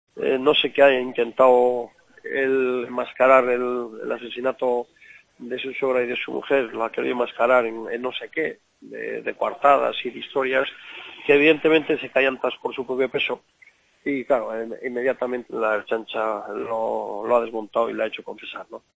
Entrevistas en Mediodía COPE